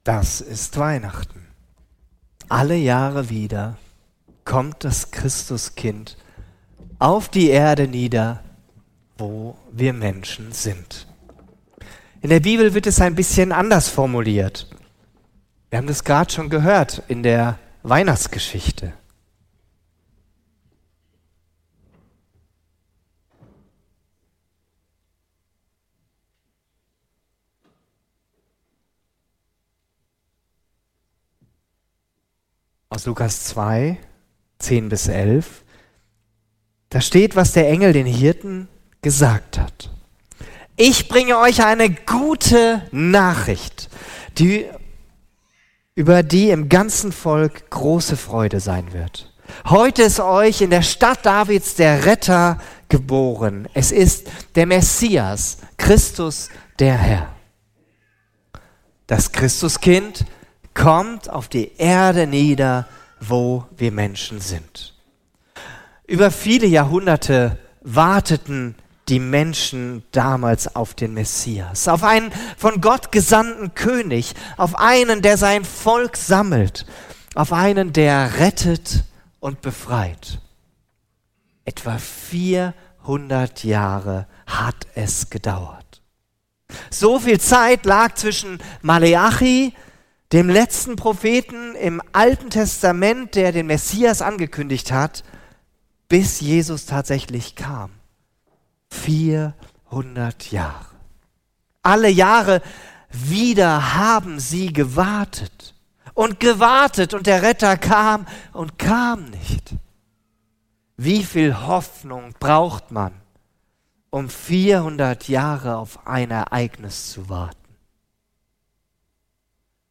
Christvesper | Alle Jahre wieder... (Lukas 2,10–11) ~ FeG Herborn Podcasts Podcast